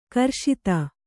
♪ karśita